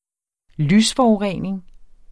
Udtale [ ˈlys- ]